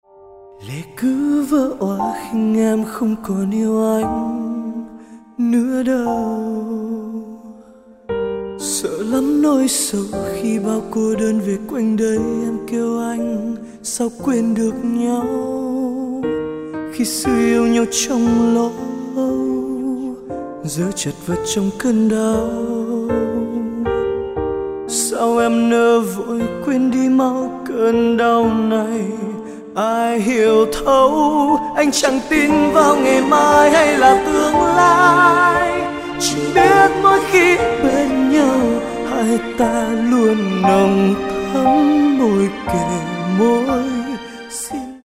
Bolero/ Trữ tình
Piano Version